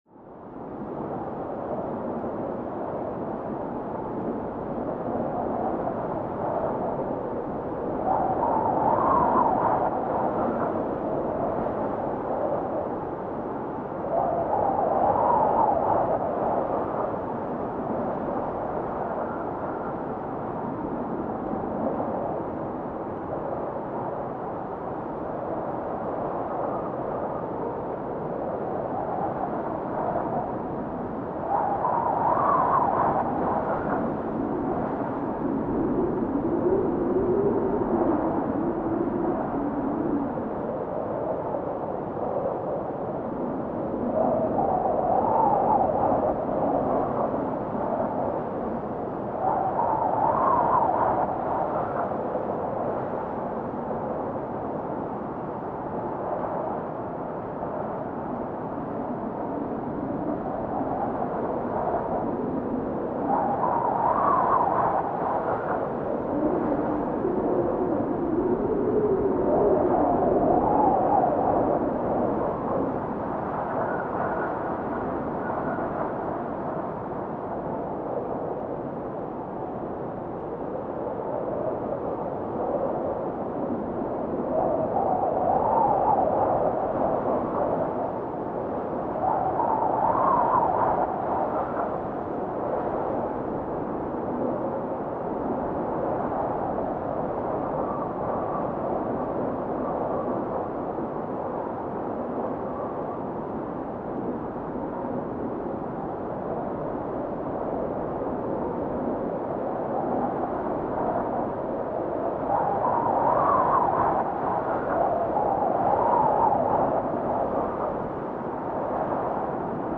cold_stormy_wind.ogg